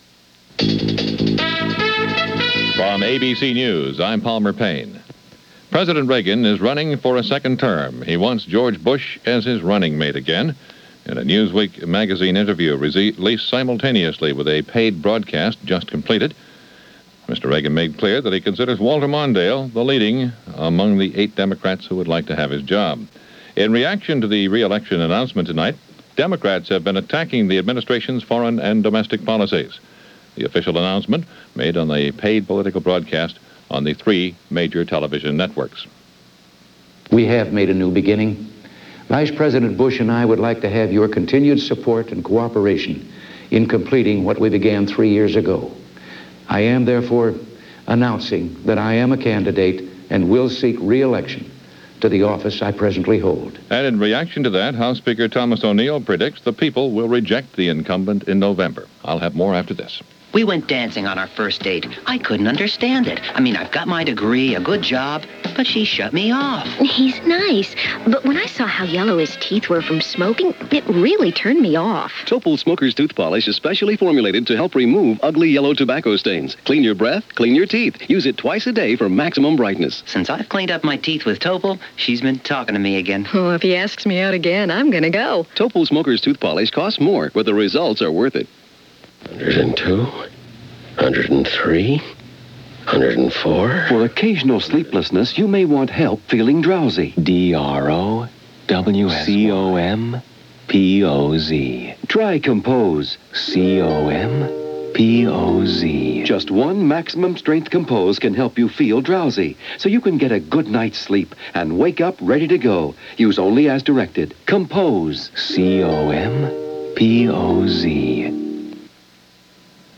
ABC Hourly News – ABC Information Network